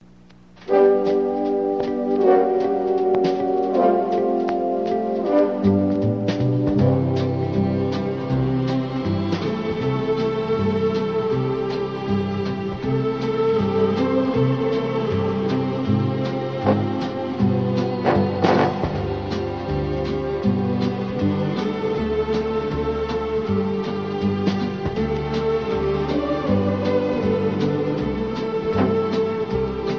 Round (two-sided)